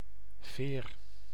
Ääntäminen
Vaihtoehtoiset kirjoitusmuodot (vanhahtava) fether Synonyymit feathering feathers horsefeathers Ääntäminen US RP : IPA : [ˈfɛðə] IPA : /ˈfɛð.ə(ɹ)/ GenAm: IPA : [ˈfɛðɚ] Tuntematon aksentti: IPA : [ˈfɛ.ðɝ]